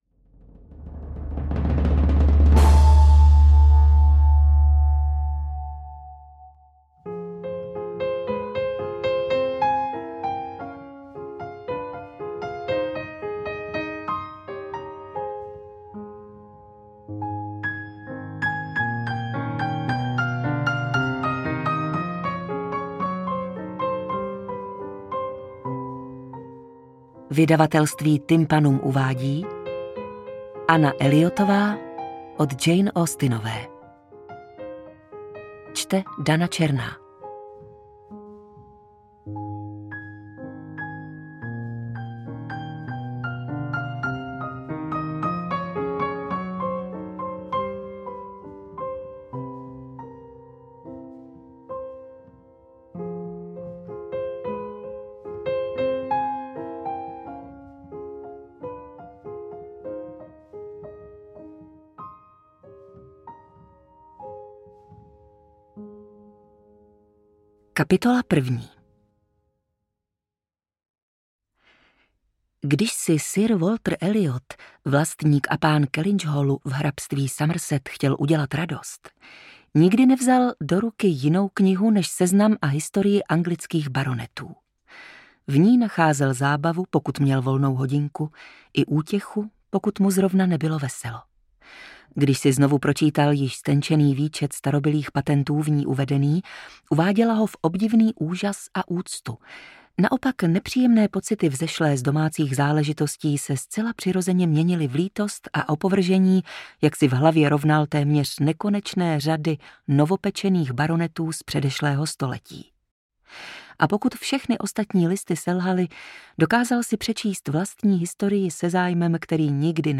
Interpret:  Dana Černá
AudioKniha ke stažení, 28 x mp3, délka 9 hod. 8 min., velikost 501,1 MB, česky